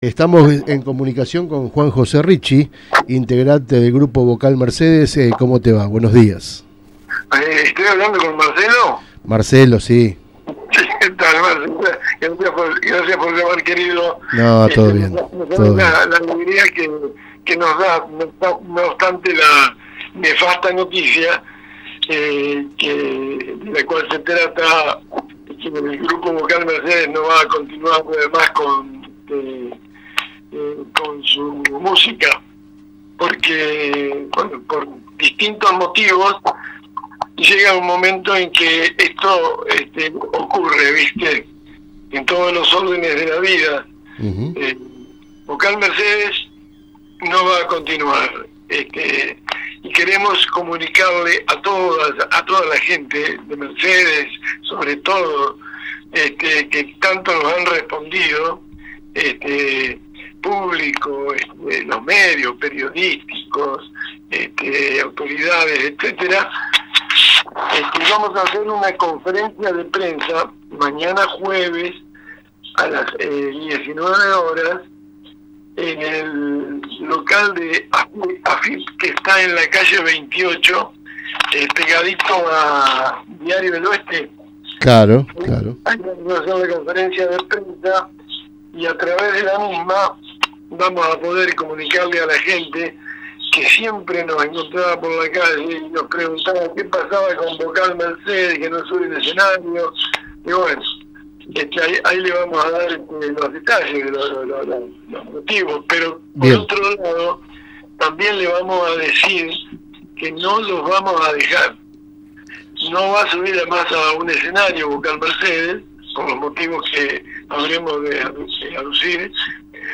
en una entrevista realizada en el programa DatoPosta Radio.